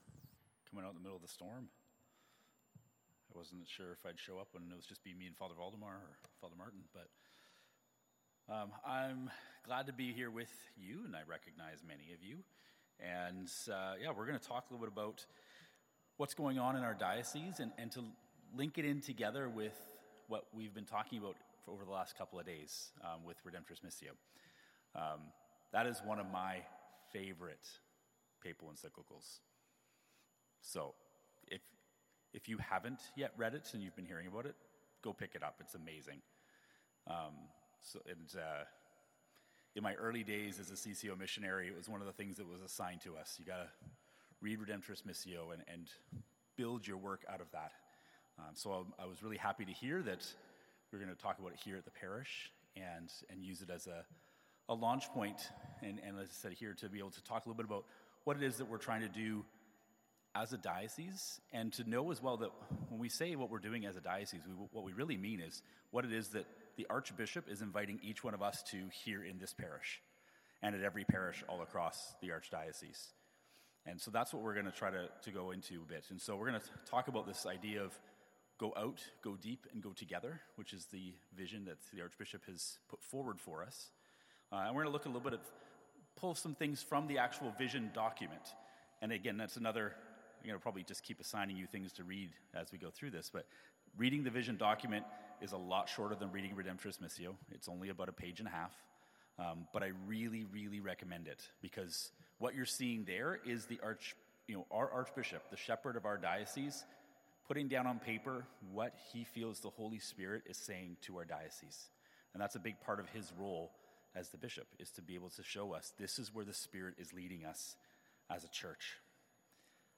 parish_lenten_retreat_day3_mp3.mp3